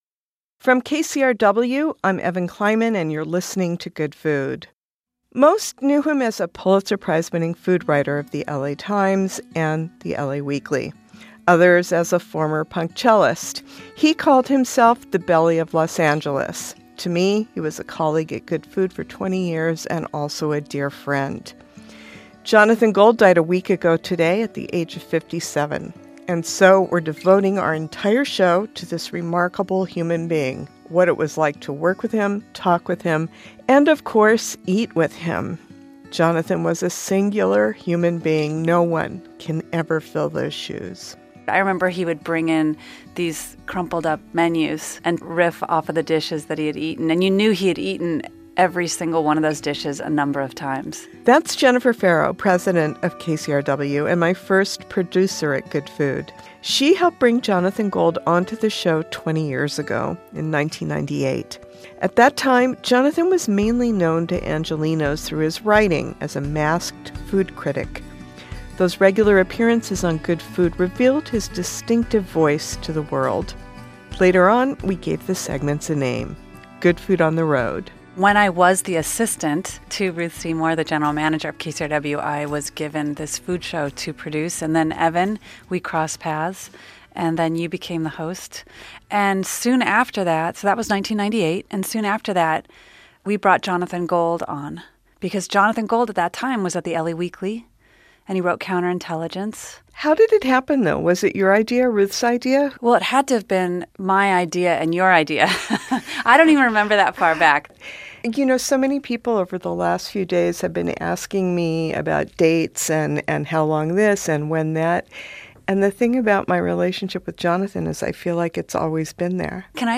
For this special tribute, we gathered the voices of colleagues, friends, chefs, and listeners to celebrate the man who taught us how to eat and live in LA.